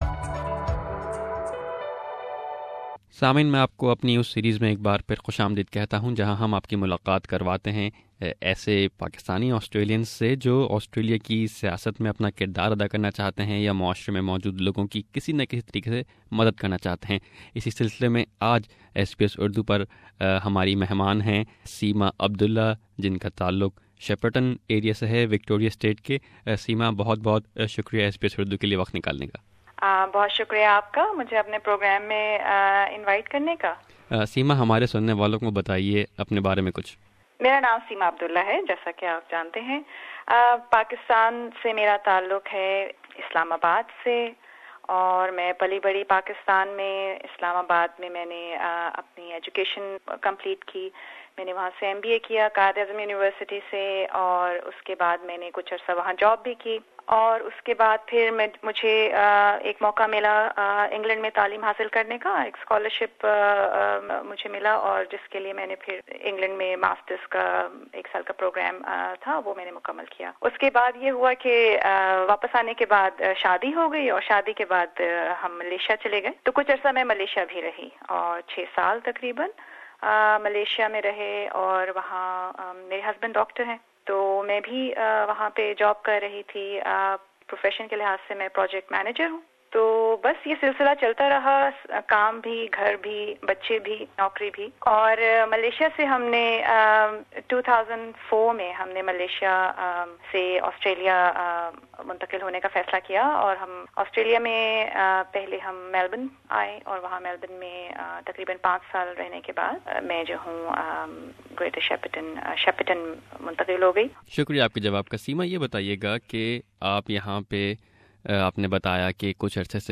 Click the audio for her complete interview in Urdu.